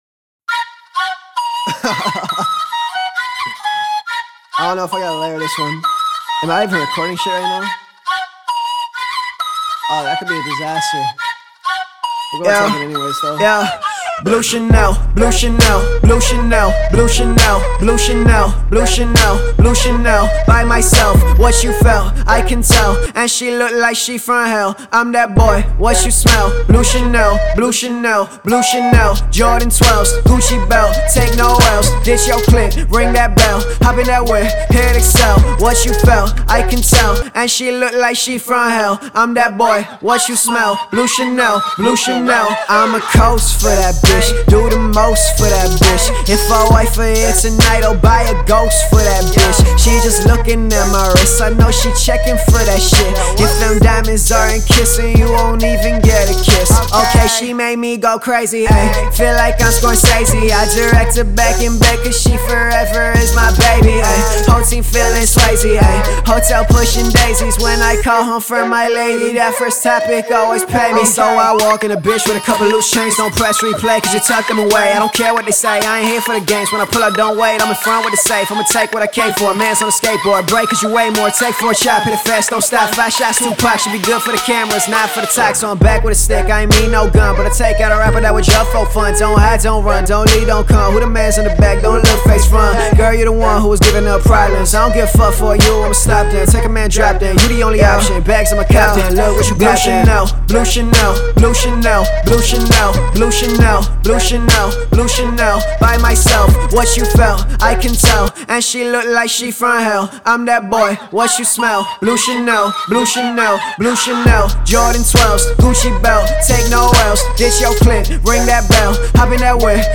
хип-хоп
hip_hop.mp3